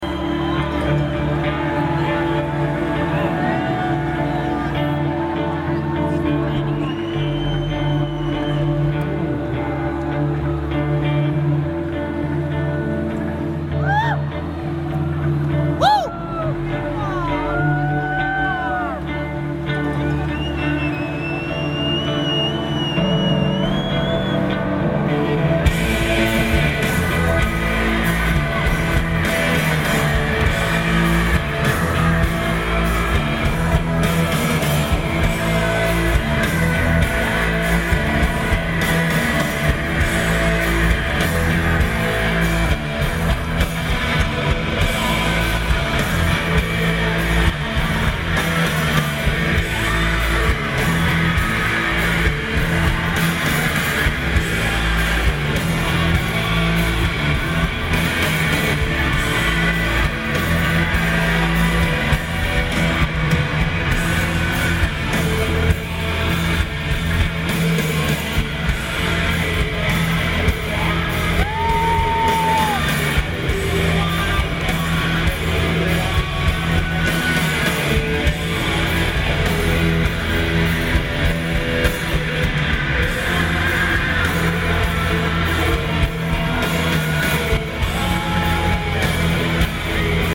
Ford Amphitheatre
Lineage: Audio - AUD (Sony ECM-DS70P + Sony MZ-M200)